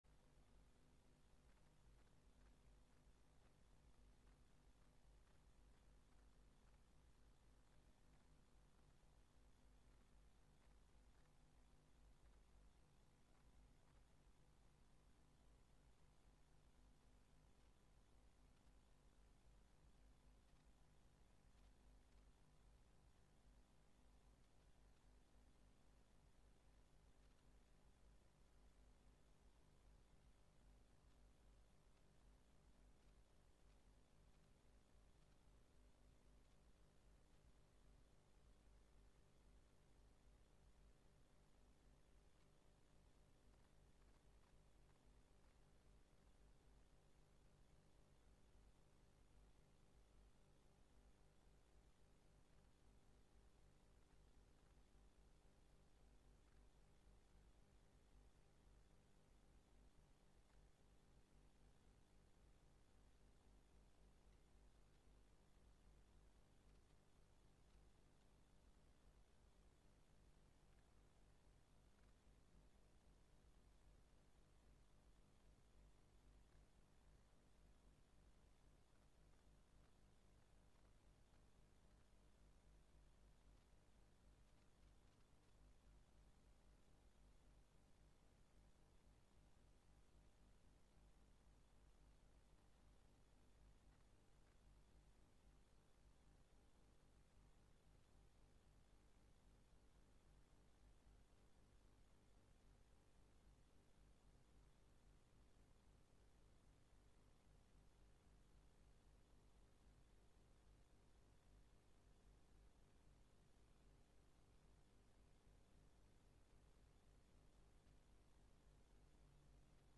Sebuah Perpisahan – Ibadah Kenaikan Tuhan Yesus (Pagi 1)